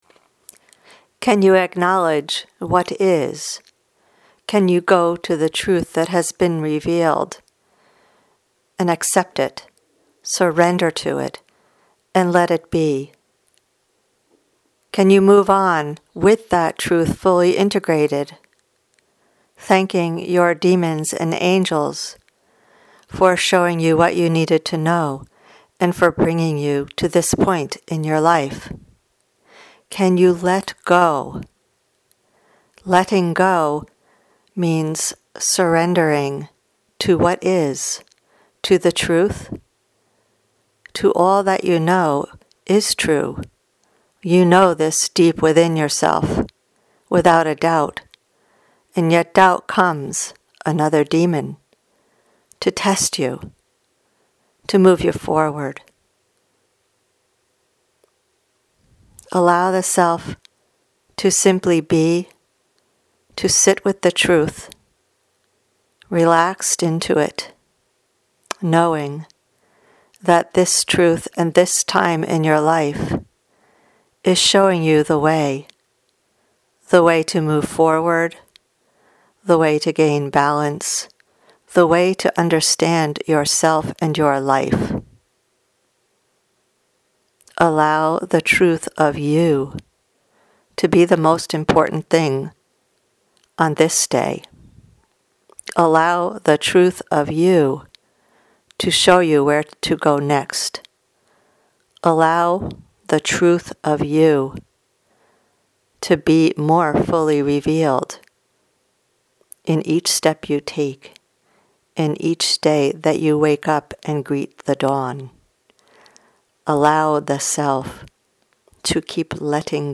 Today’s audio channeling poses some good questions as we deal with what needs attention within and without. Where should your focus go?